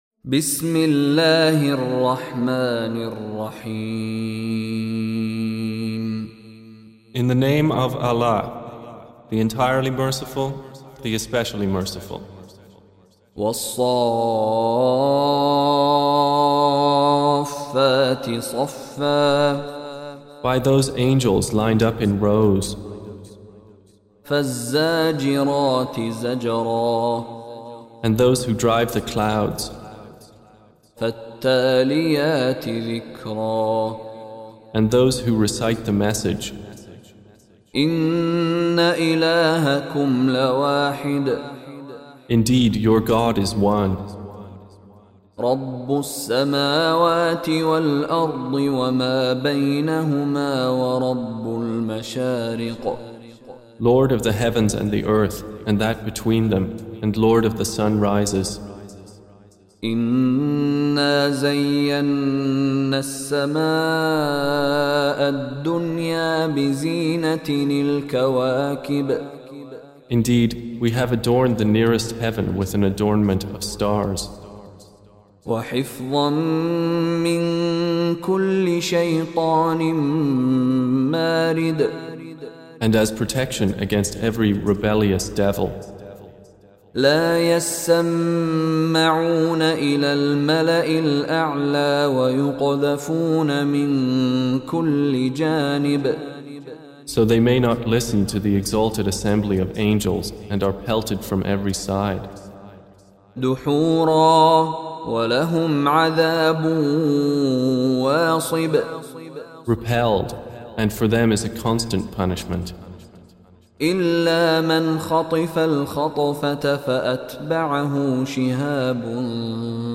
Audio Quran Tarjuman Translation Recitation Tarjumah Transliteration
Surah Repeating تكرار السورة Download Surah حمّل السورة Reciting Mutarjamah Translation Audio for 37. Surah As-S�ff�t سورة الصافات N.B *Surah Includes Al-Basmalah Reciters Sequents تتابع التلاوات Reciters Repeats تكرار التلاوات